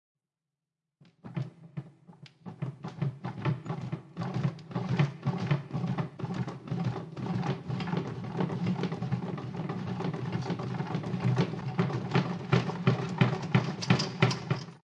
橱柜摇晃
描述：地震发生时柜子的声音。
Tag: 地震